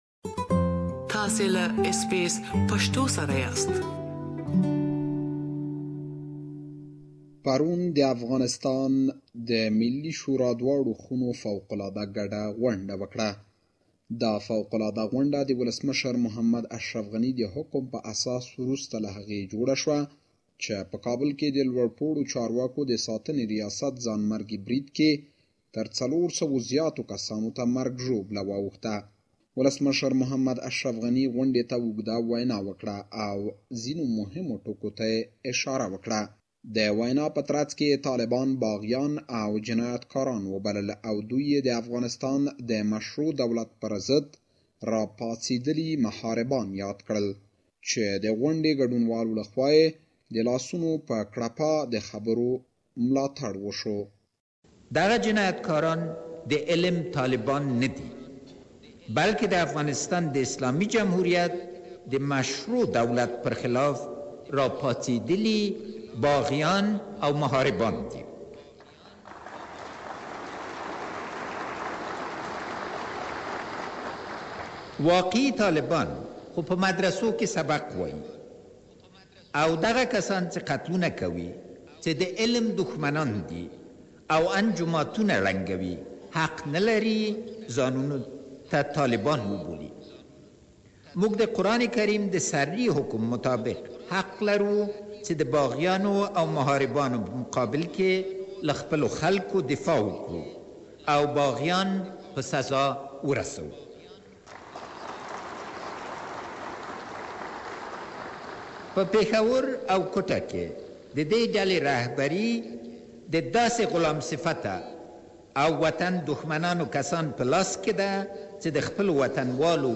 Afghan President Ghani’s recent controversial speech to parliament
After last weeks deadliest attack in Kabul which has shocked the nation, Afghan president Mohammad Ashraf Ghani has delivered a controversial speech to Afghanistan's lower and upper house representatives and has outlined the government's plan to tackle the present challenges. We have gathered some of the highlight of president Ghanis speech that you can listen to it here.